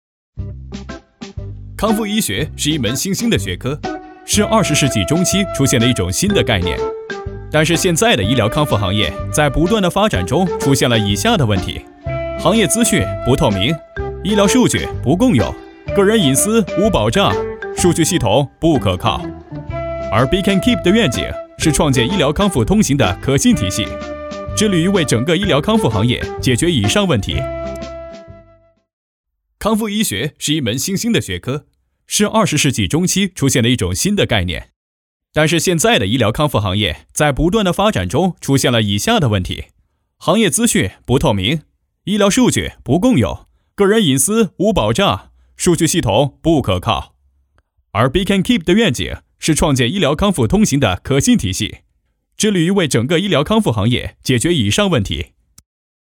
男35号配音师
代表作品 Nice voices 飞碟说 广告 旁白 英文 专题片 飞碟说-男35-康复医学.mp3 复制链接 下载
6年从业经验，声音风格年轻活力，活跃明快，温暖动人。